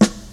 • 90s Fat Soul Steel Snare Drum A Key 03.wav
Royality free snare drum sound tuned to the A note.
90s-fat-soul-steel-snare-drum-a-key-03-CU1.wav